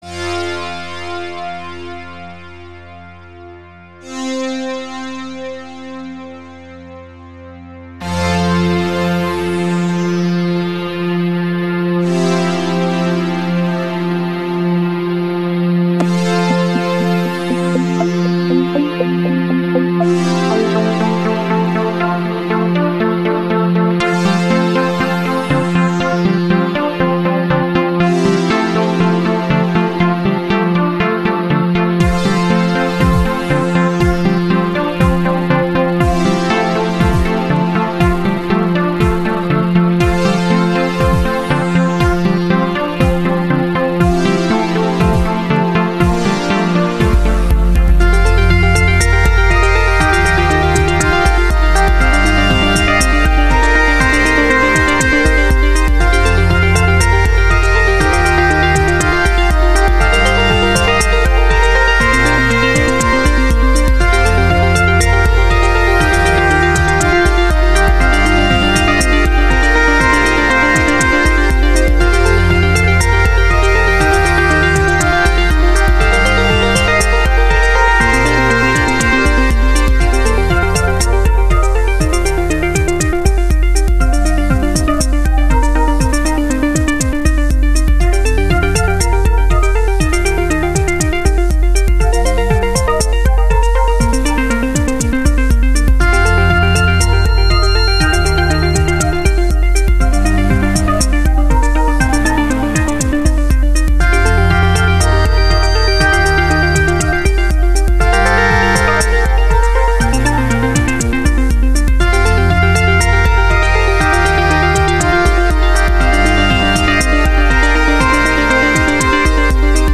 This one reminds me of good old adventure and fantasy games.
It has a nice/interesting amount of different instruments, sounds and melodies.